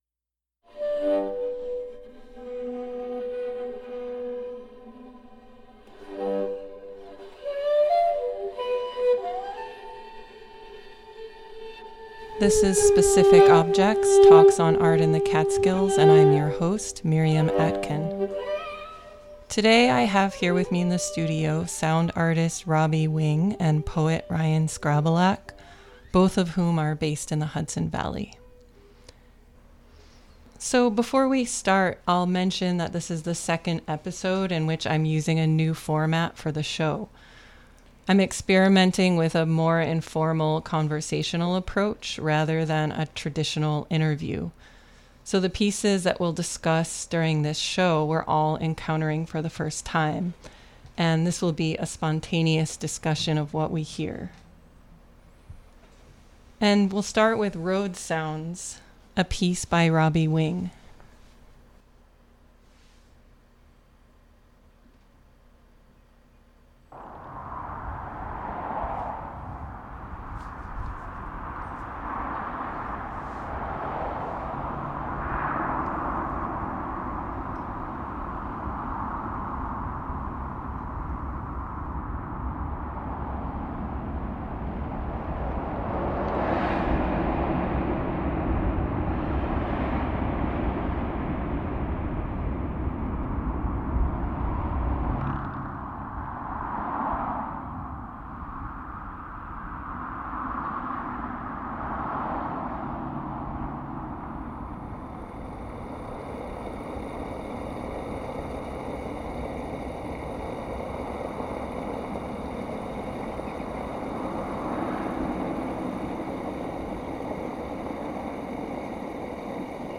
"Specific Objects" is a monthly freeform discussion
Intro music